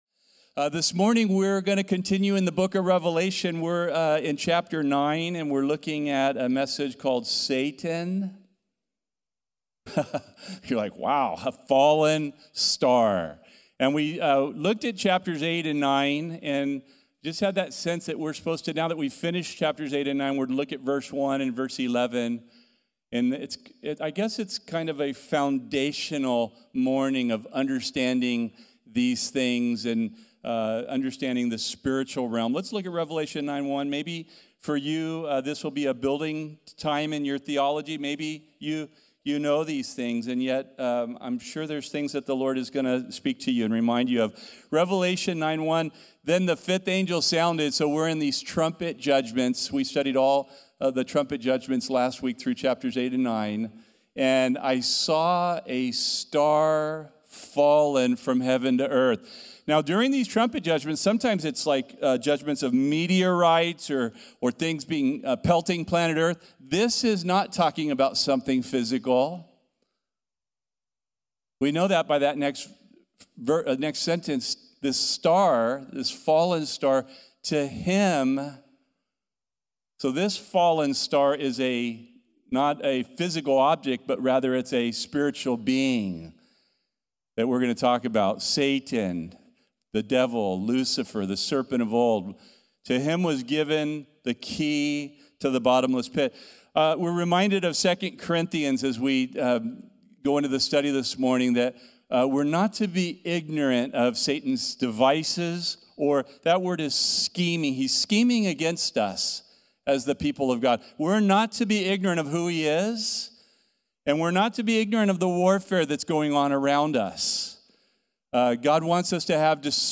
The Bible study given at Calvary Chapel Corvallis on Sunday, March 8, 2026..